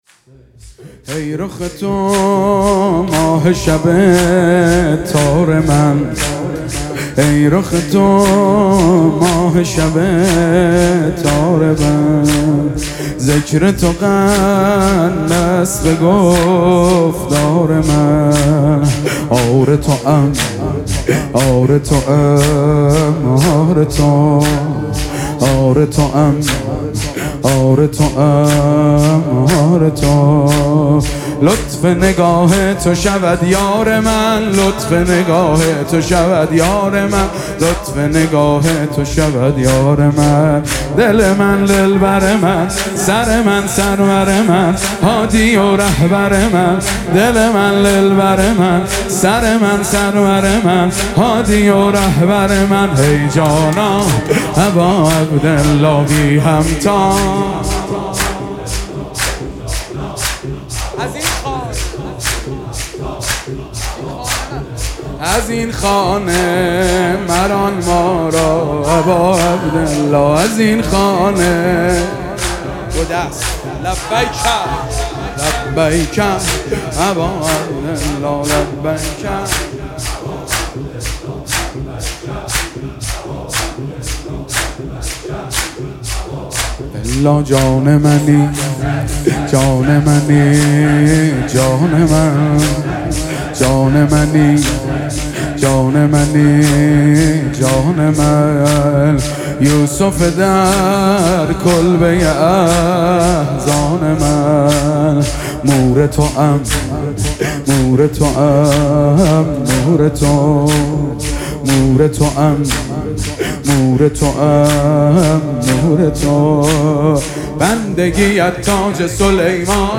مرثیه‌سرایی و نوحه‌خوانی